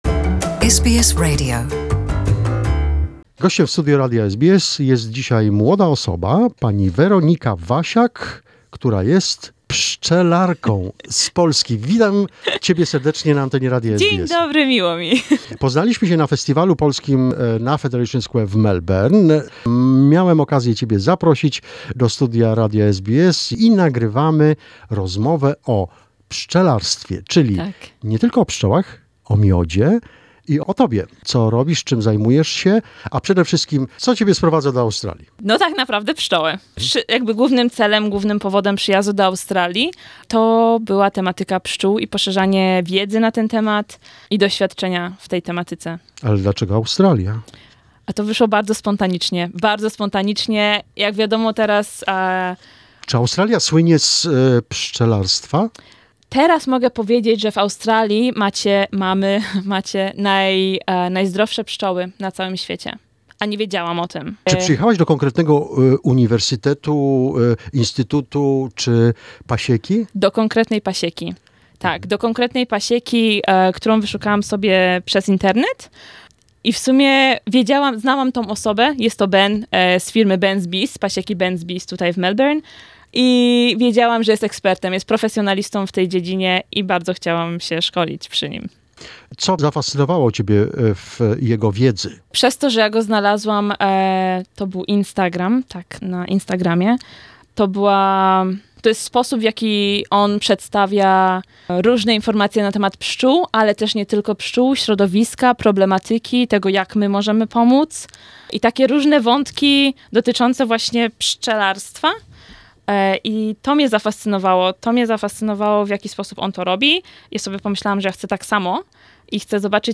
odkrywa "najsłodsze sekrety" pszczół oraz jak pszczoły robią miód ... dziś, pierwsza część rozmowy.